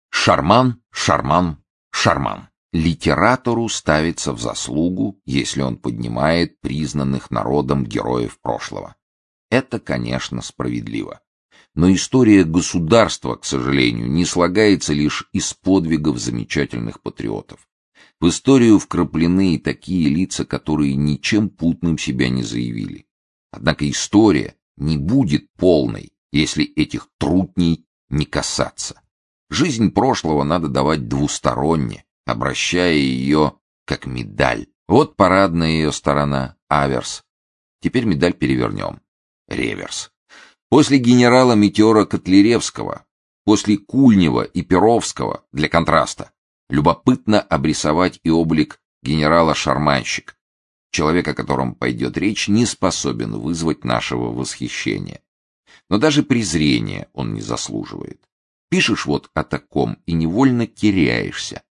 Aудиокнига Тайный советник Автор Валентин Пикуль Читает аудиокнигу Сергей Чонишвили.